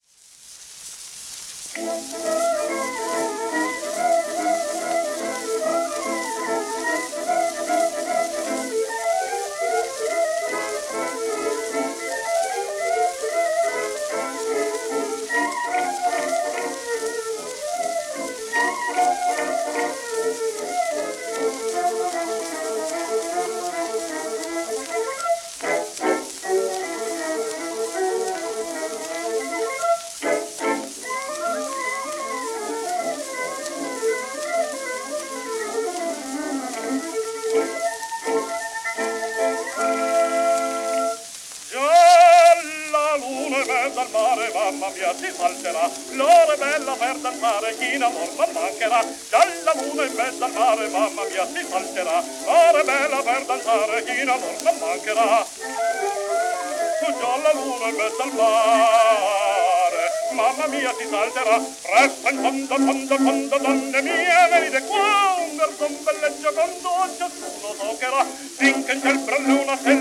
w/オーケストラ
1911年録音
旧 旧吹込みの略、電気録音以前の機械式録音盤（ラッパ吹込み）